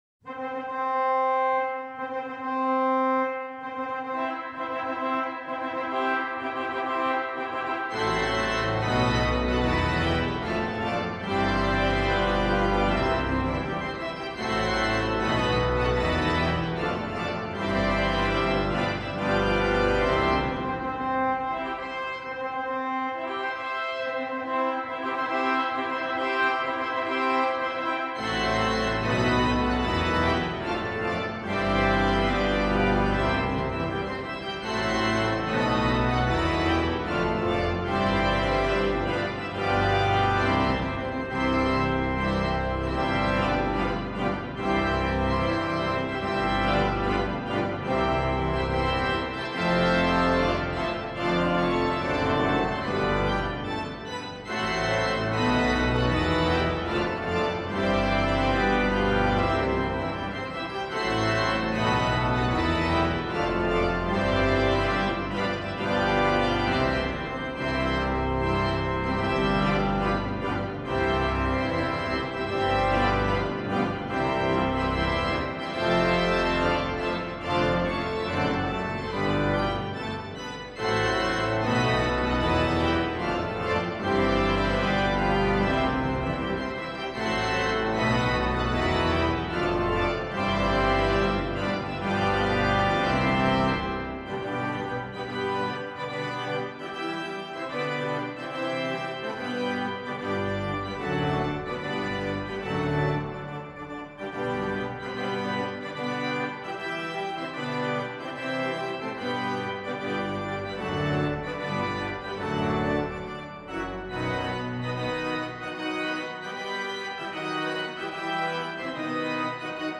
När man går ut ur kyrkan vill man ju ha något mera gladare.